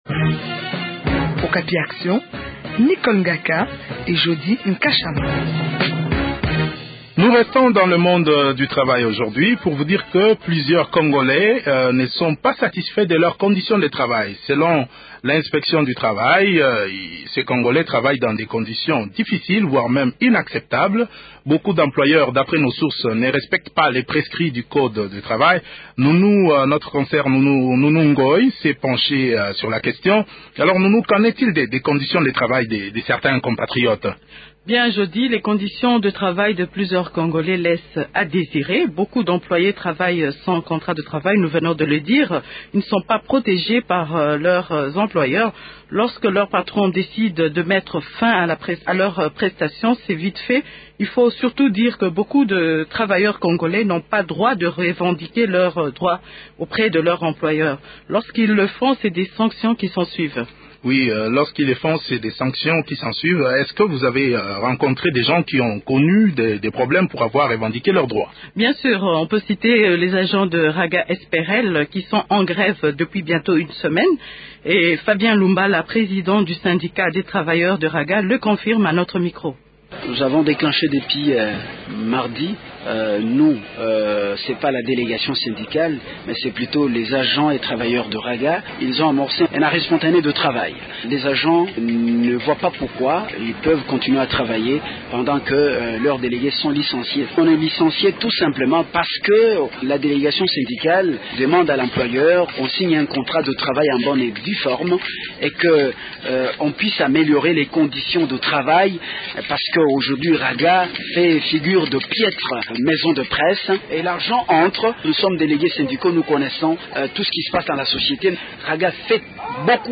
reçoivent de nouveau monsieur Monsieur BOLA, secrétaire Général au ministère du travail.